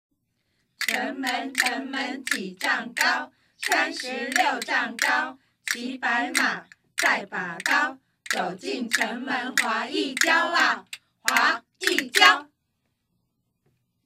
已錄製人聲(VocalA)檔案 音樂(MusicA)檔案 混音後檔案
Vocal_A.wma